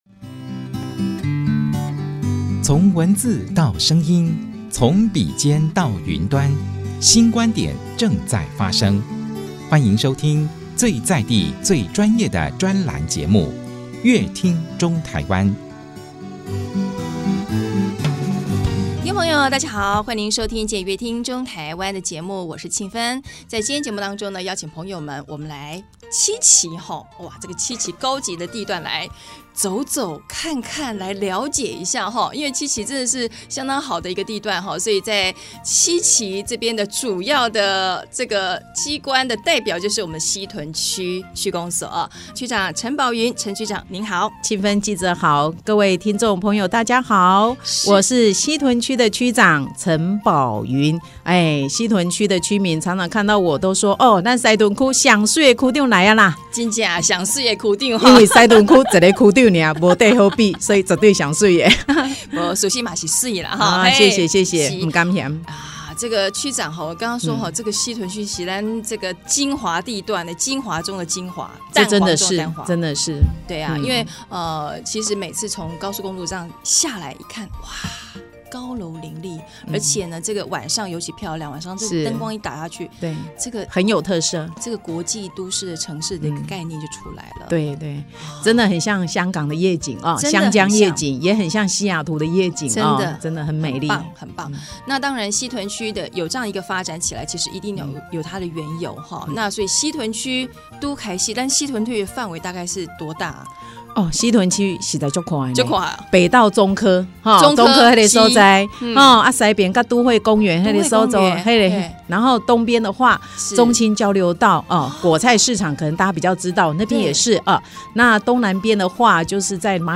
本集來賓：台中市西屯區陳寶雲區長 本集主題：「黃金七期帶動西屯發展」 本集內容： 夜幕低垂，高樓林立的台中市七期重劃區閃耀著國際都市光芒，一棟棟特色建築彷彿魔法般換上斑斕色彩，置身其間人跟著也時尚了起來。